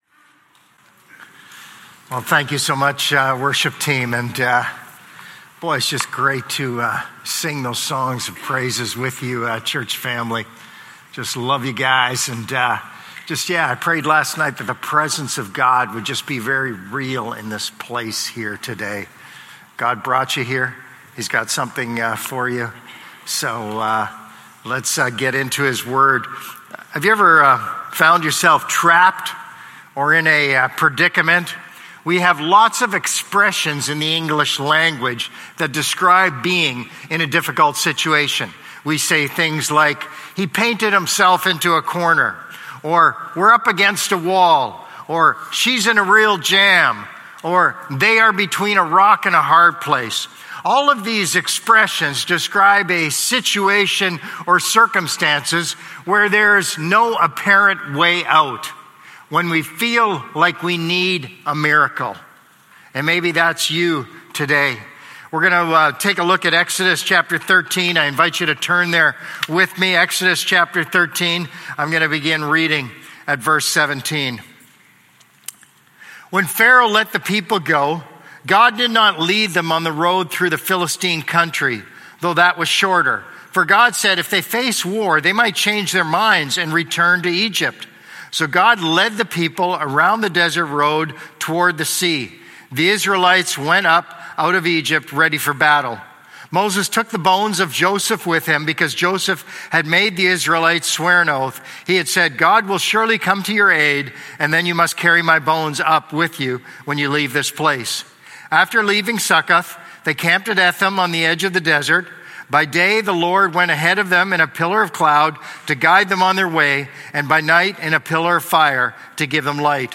Sermons | Gracepoint Community Church